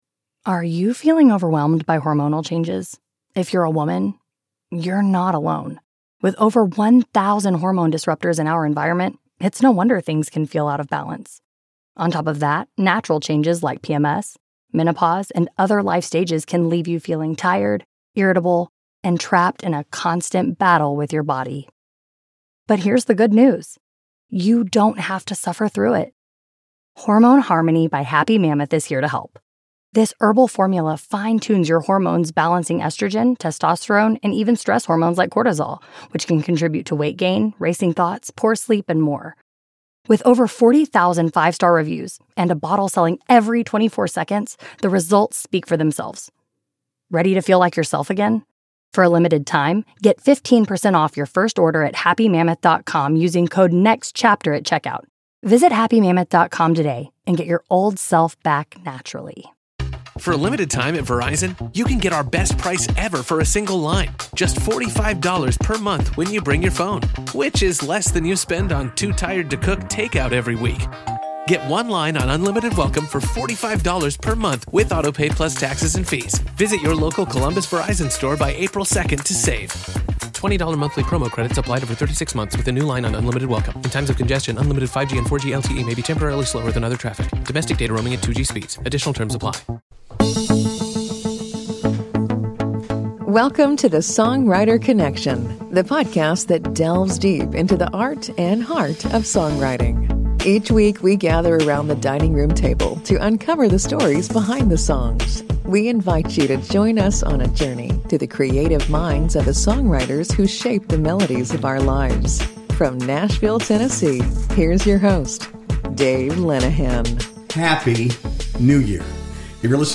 Join us as we gather around the dining room table to hear their stories, explore their music, and celebrate the way art and healing intersect in the new year. This episode is filled with heart, passion, and, of course, great tunes you won’t want to miss.